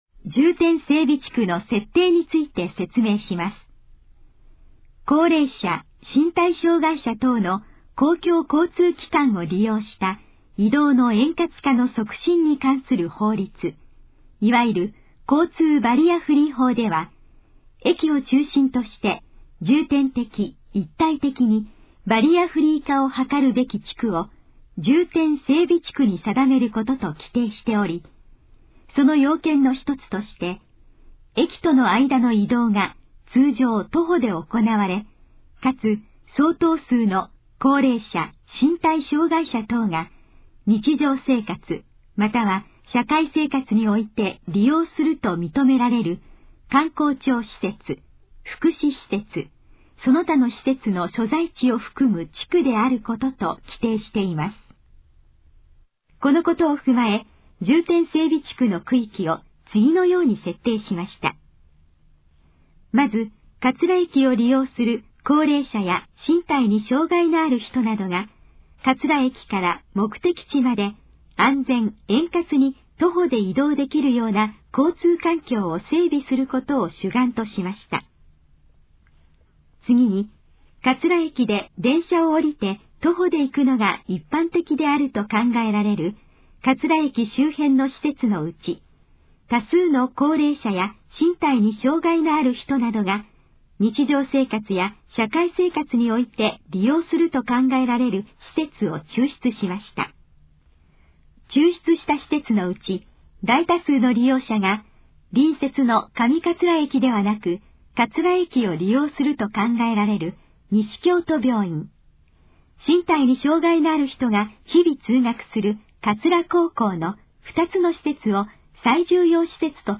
このページの要約を音声で読み上げます。
ナレーション再生 約303KB